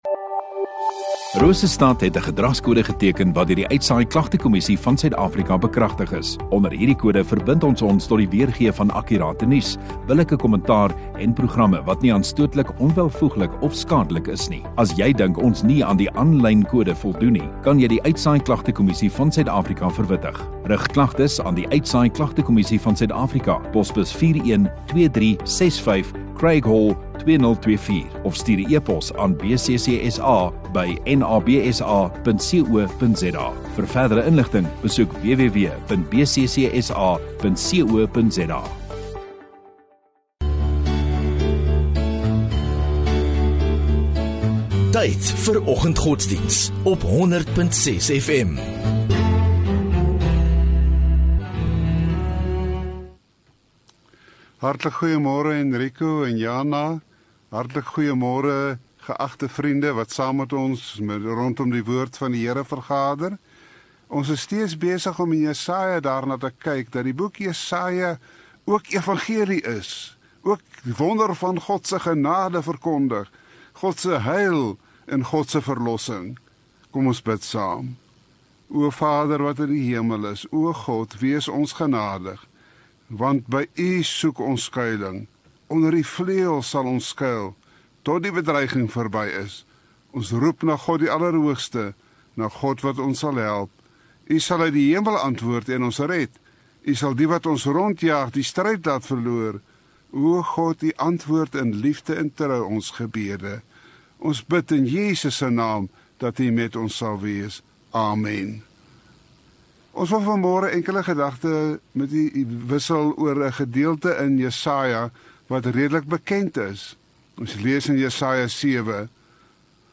Woensdag Oggenddiens